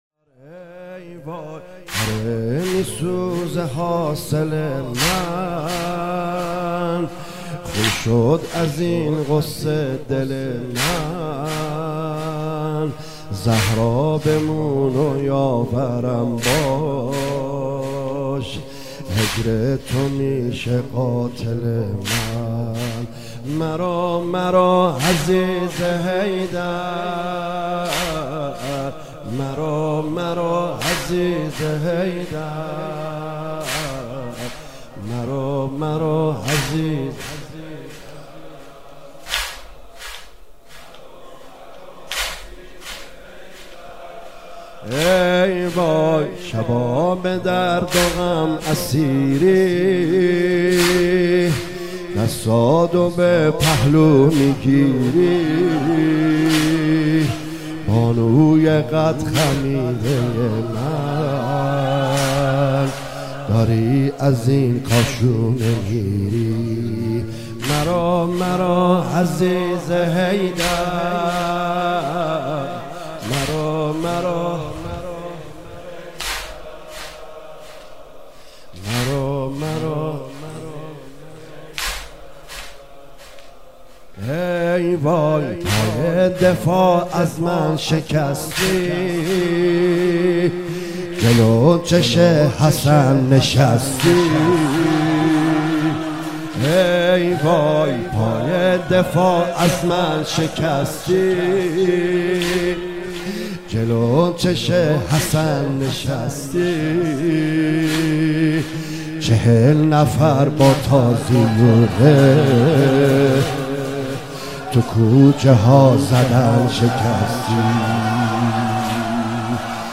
مداحی ایام فاطمیه